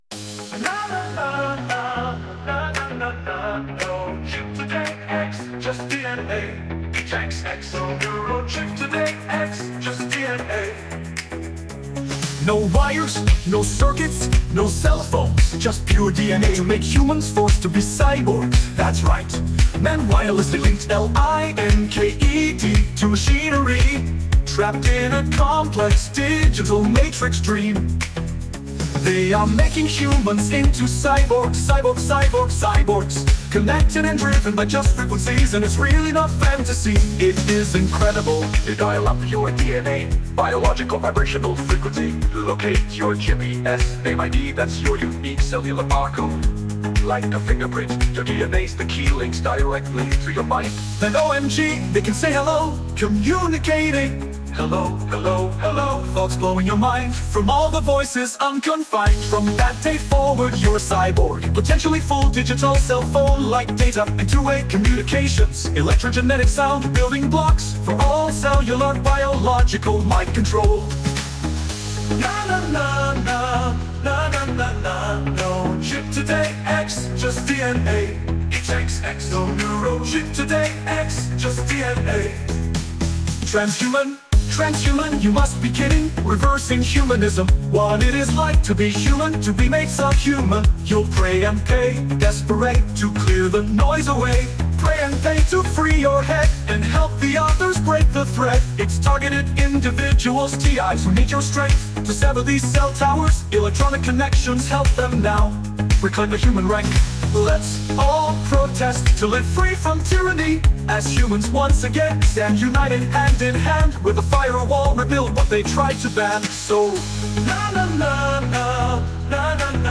Driving 1980's Disco-popmelodiccatchychorus in minor